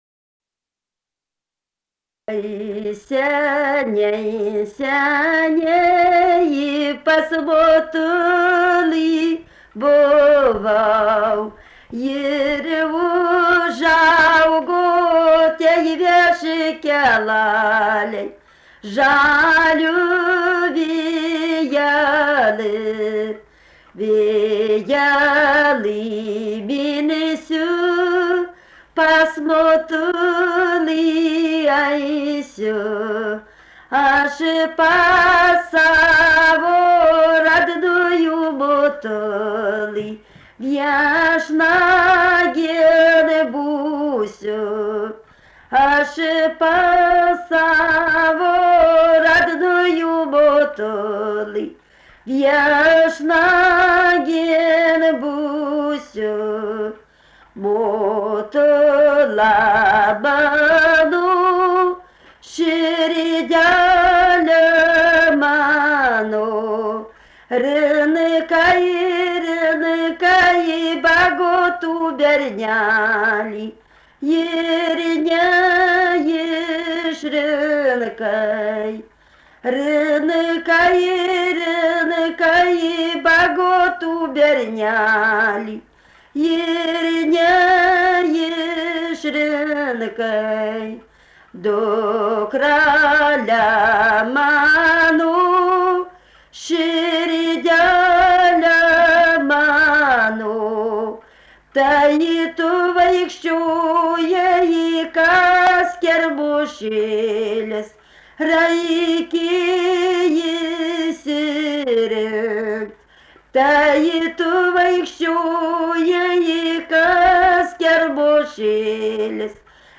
Rudnia
vokalinis
2 balsai